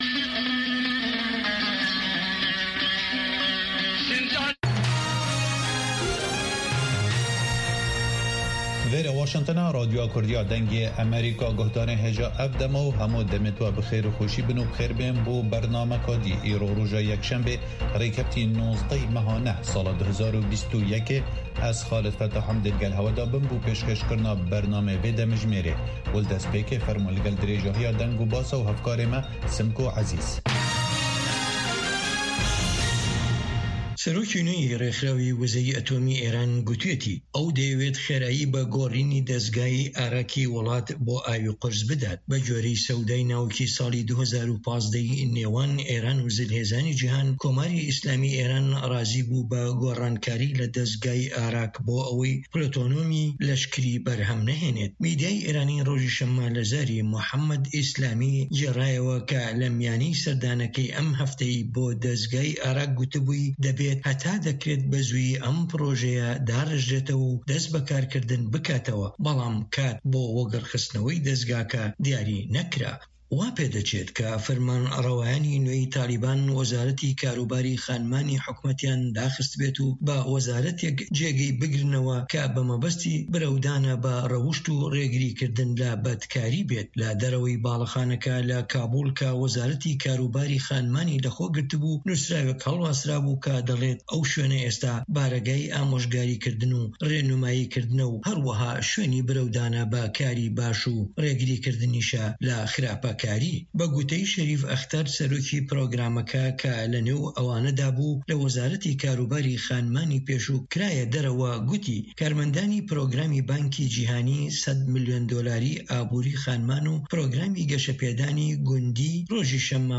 هەواڵەکانی 3 ی پاش نیوەڕۆ
هەواڵە جیهانیـیەکان لە دەنگی ئەمەریکا